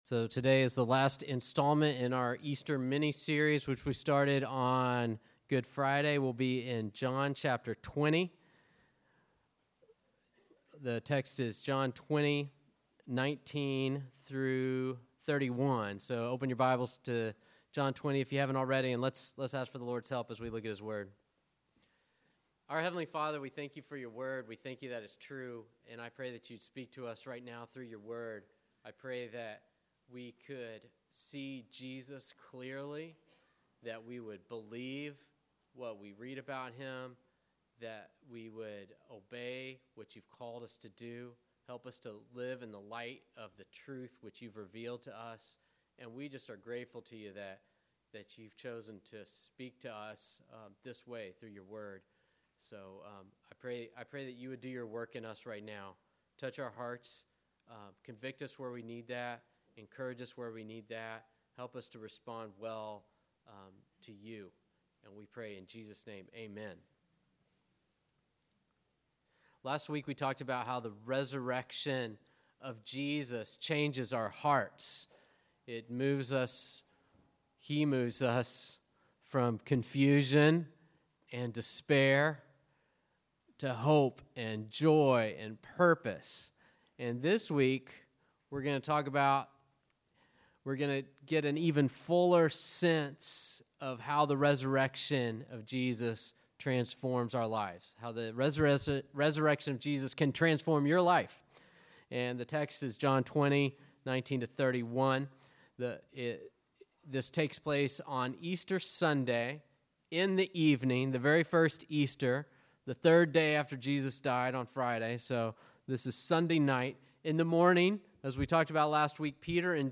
Listen to sermons by our pastor on various topics.